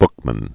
(bkmən, bŭk-), Frank Nathan Daniel 1878-1961.